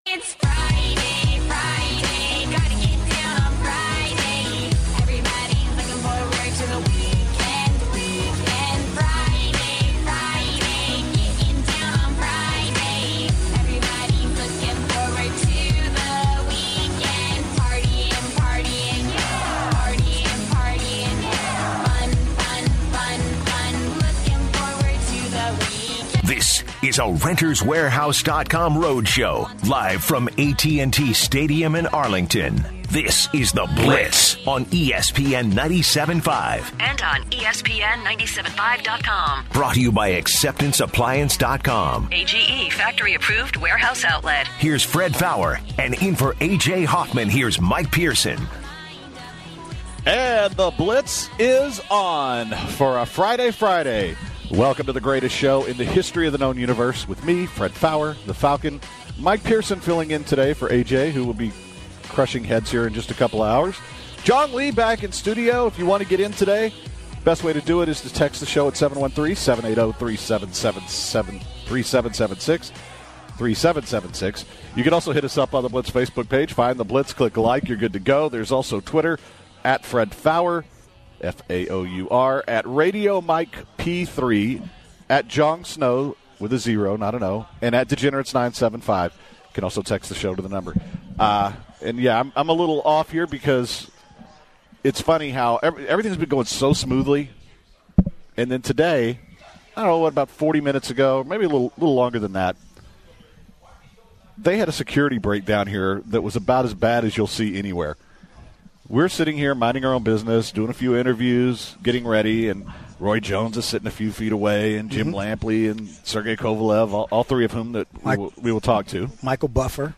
recording the show from Dallas, Texas